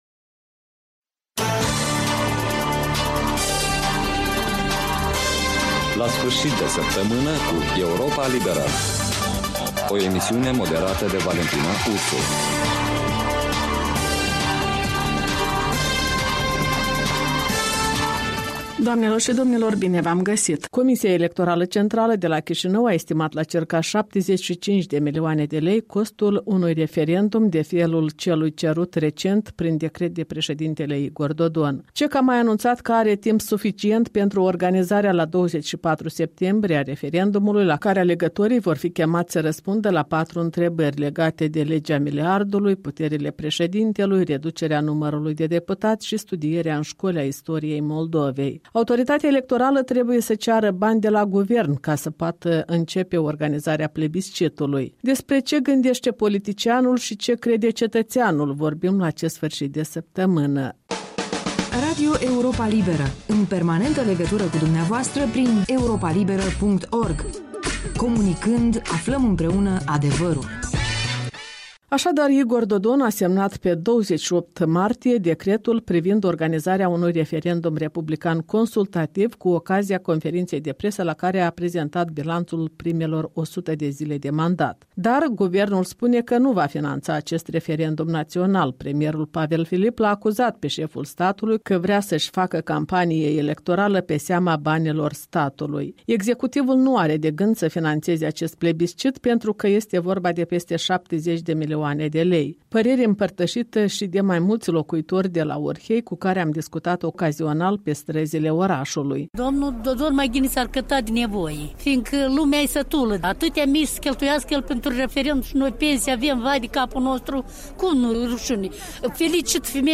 în dialog cu locuitori din Orhei